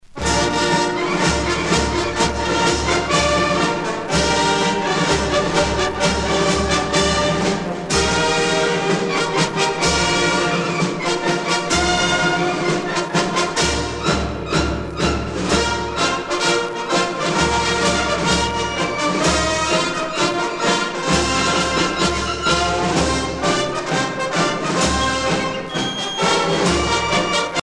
gestuel : à marcher
circonstance : militaire